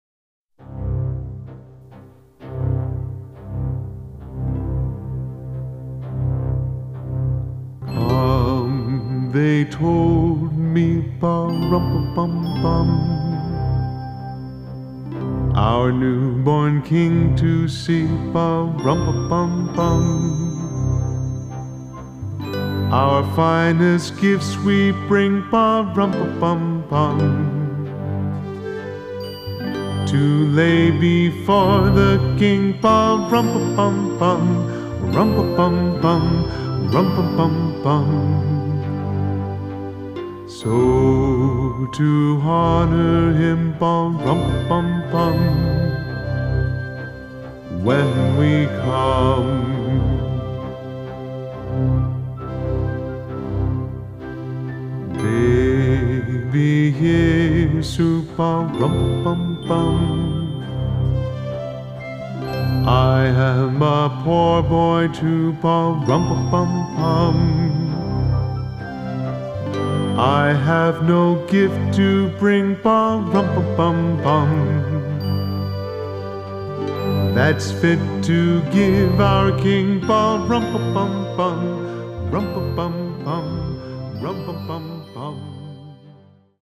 piano/keyboard/vocals